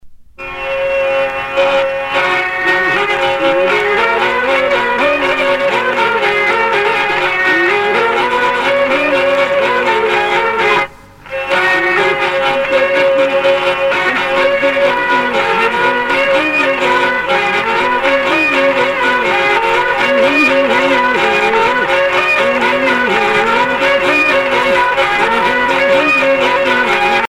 Usage d'après l'analyste gestuel : danse
Sonneurs de vielle traditionnels en Bretagne